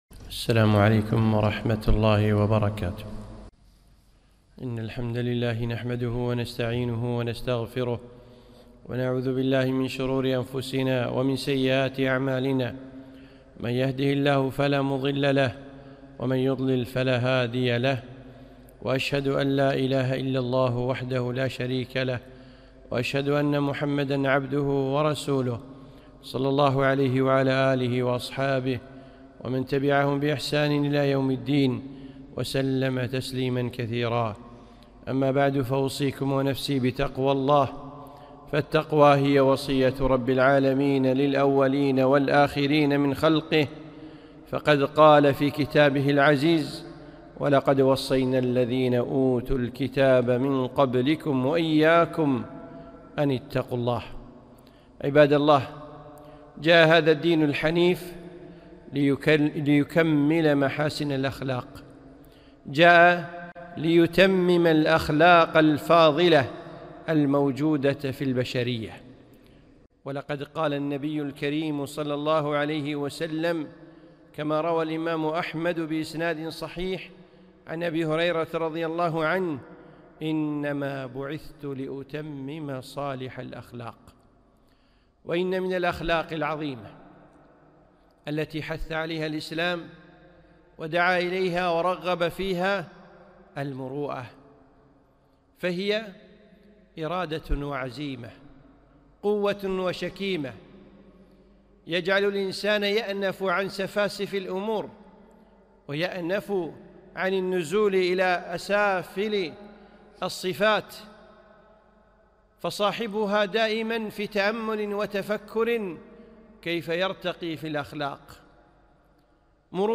خطبة - الــمــروءة